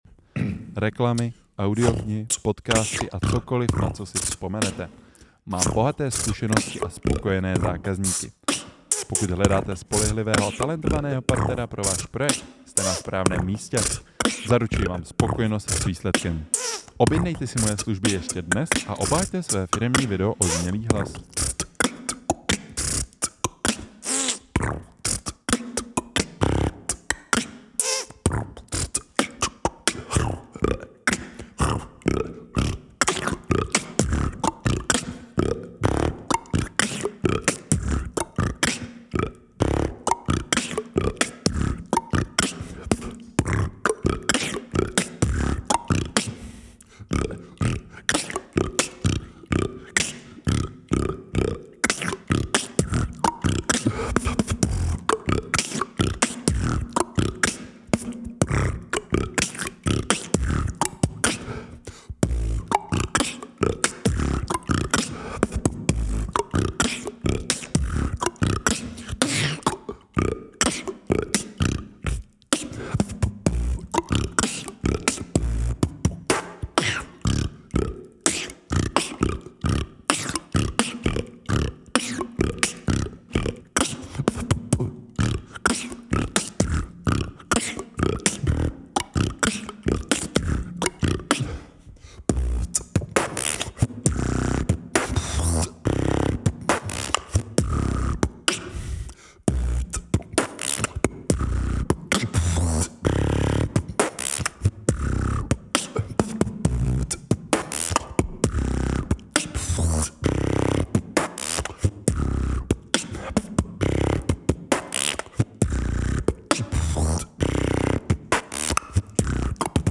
Umím: Voiceover